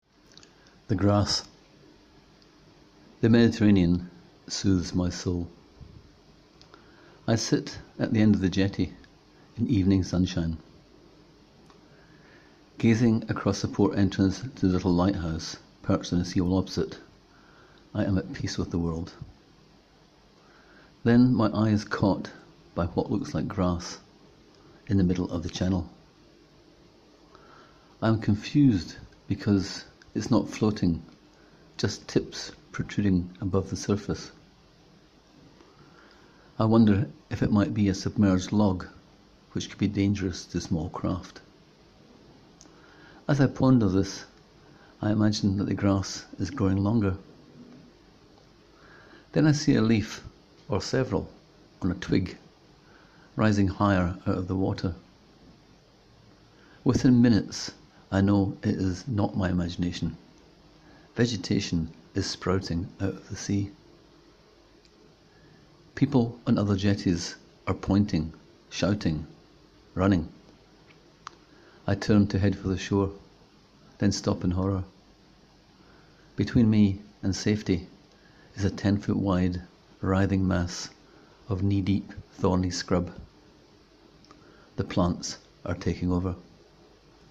Click here to hear the author read aloud this 90-second story: